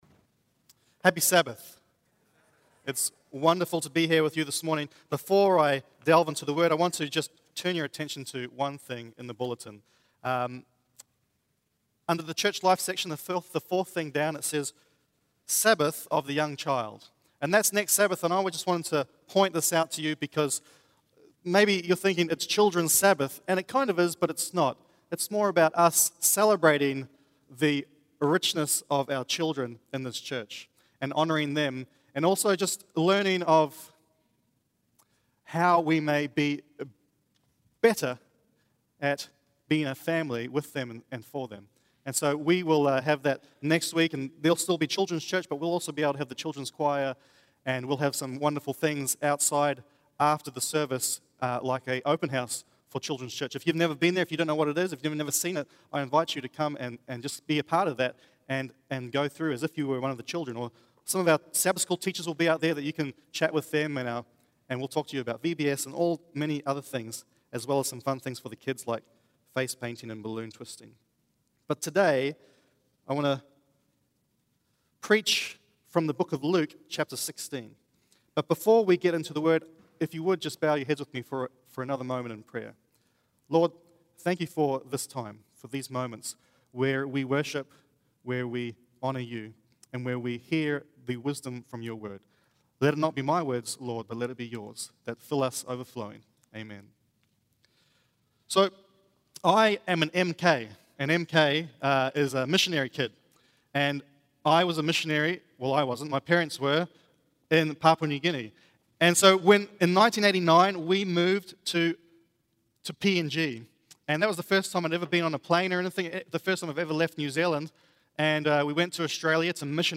SERMONS 2013